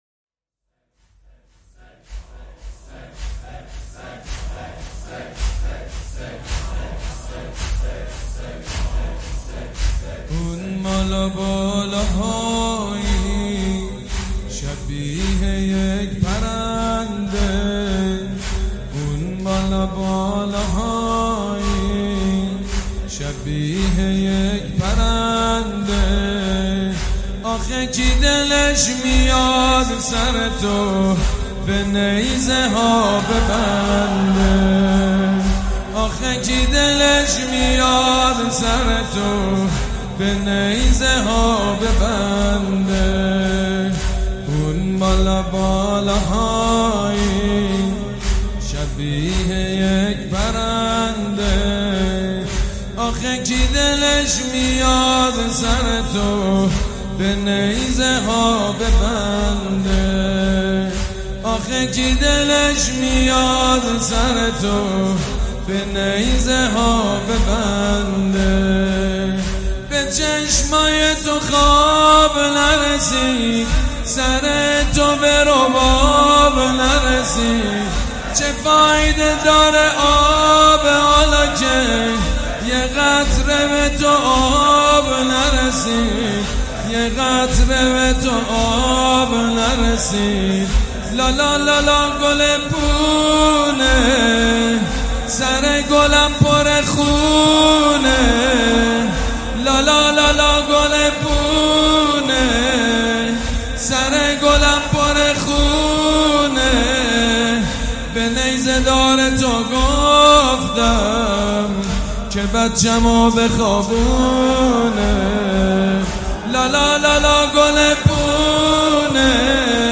حسینیه ی ریحانه الحسین شب هفتم محرم97